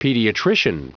Prononciation du mot pediatrician en anglais (fichier audio)
pediatrician.wav